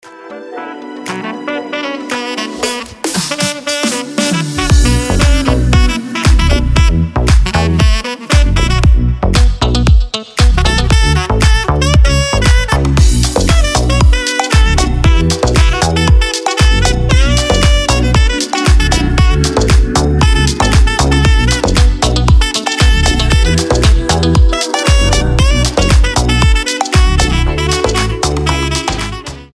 • Качество: 320, Stereo
deep house
без слов
инструментальные
Саксофон
Приятный инструментальный дипчик